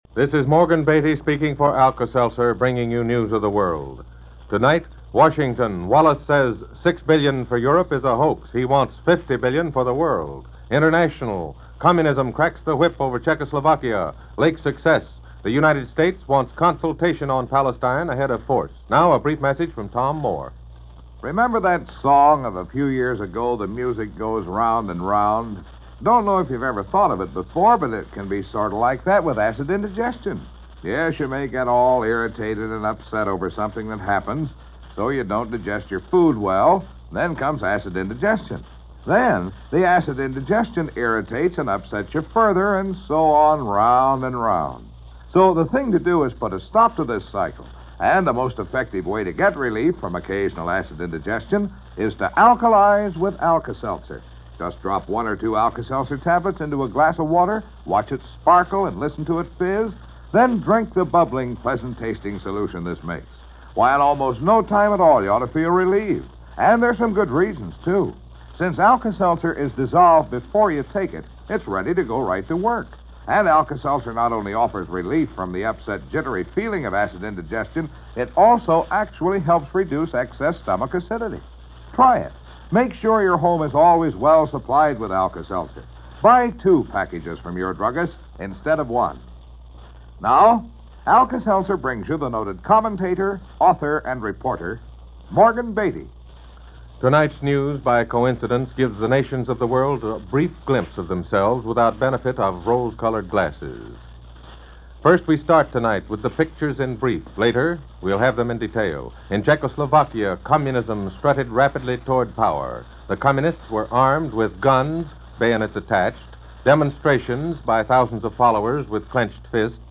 Perhaps the notion that this newscast was sponsored by Alka-seltzer was more prophetic than first imagined.